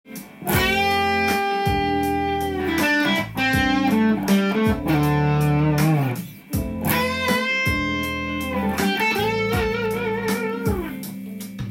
玄人系チョーキングでギターソロ
こんな感じで微妙な音程の間を行き来するので
危険な雰囲気を出しながら安全圏で着地するという